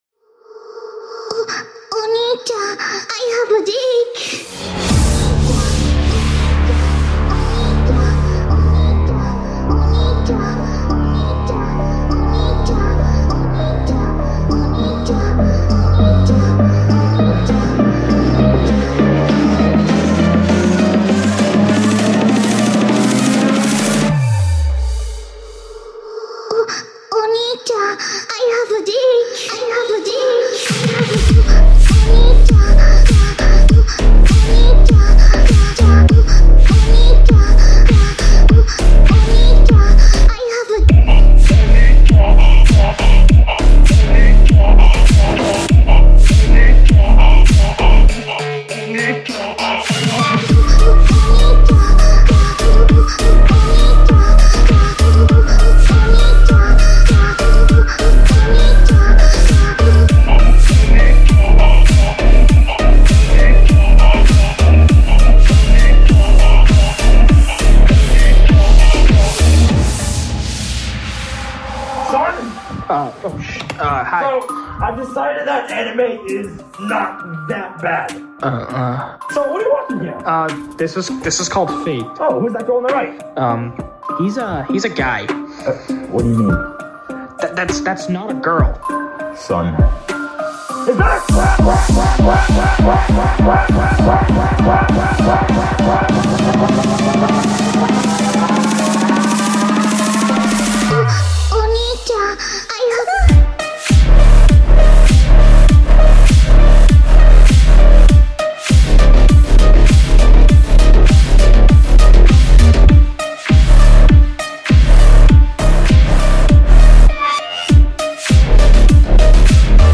Onii_chan_bass_boosted.mp3